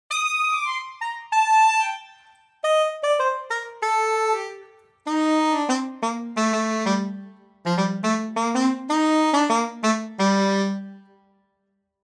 Das Solo-Saxofon könnte auch außerhalb klassischer oder cineastisch-symphonischer Projekte Karriere machen:
Sein Klang ist ausgesprochen klar, nah und intim und kann auch bei Pop und Jazz Akzente setzen.